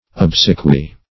Obsequy \Ob"se*quy\, n.; pl. Obsequies.
obsequy.mp3